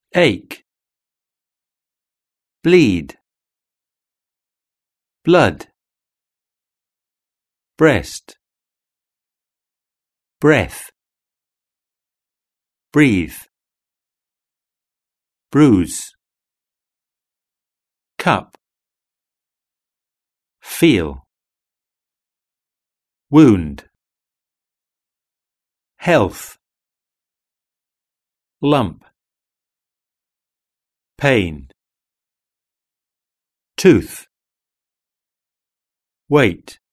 11. Pronunciation: Vowel sounds.